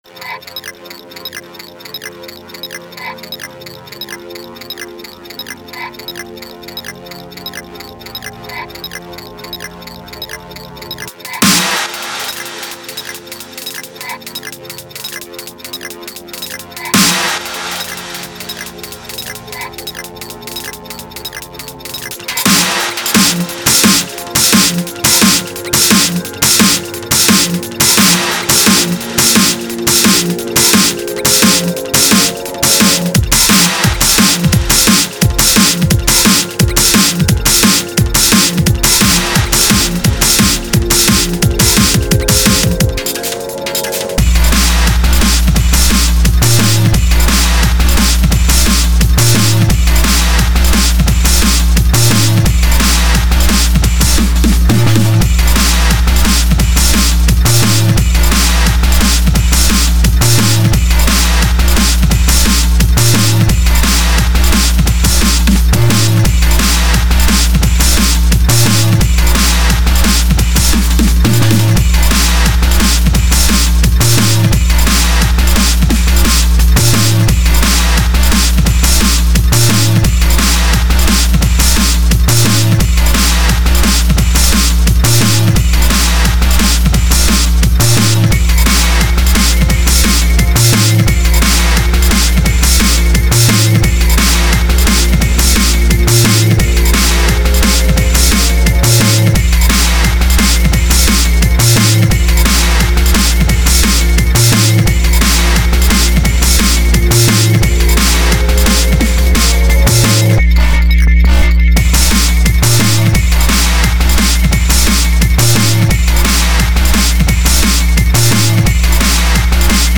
TECHSTEP